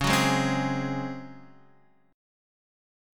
C#M7sus4 chord